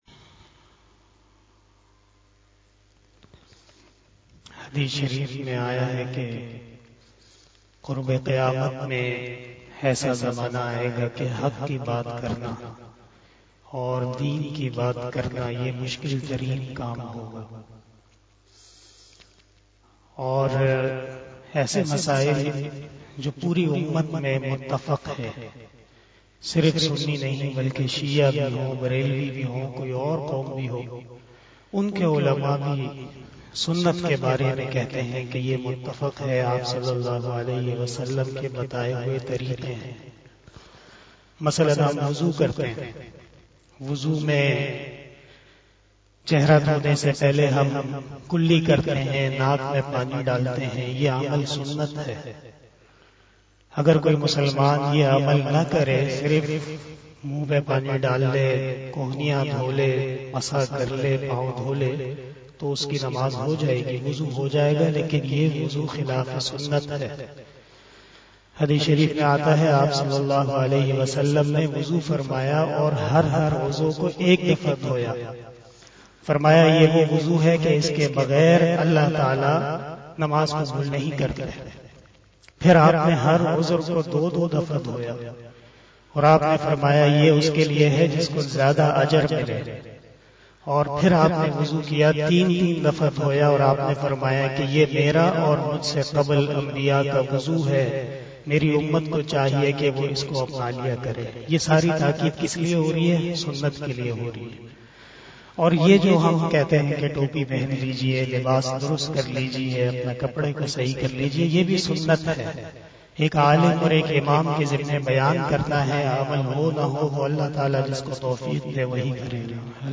012 After Asar Namaz Bayan 17 February 2022 ( 15 Rajab ul Murajjab 1443HJ) Thursday